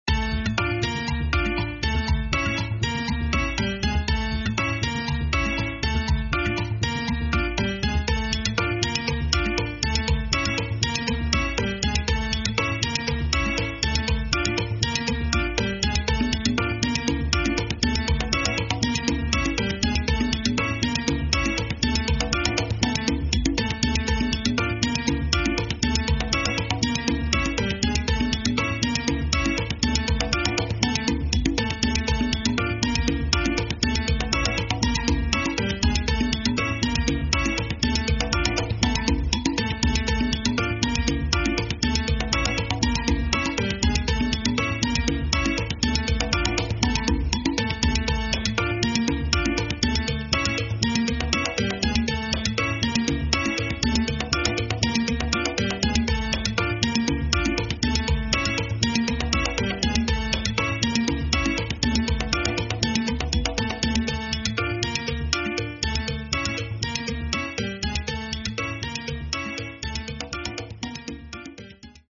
Estilo: Latino